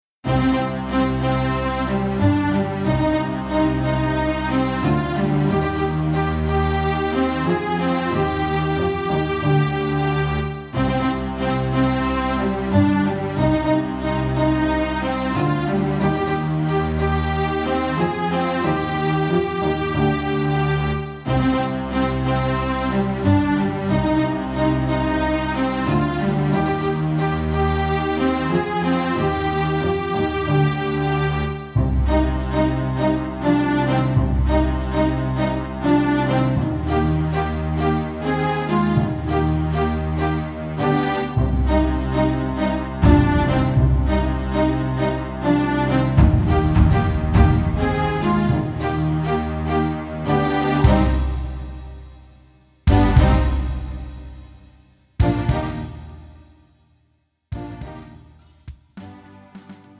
음정 -1키 3:15
장르 가요 구분 Pro MR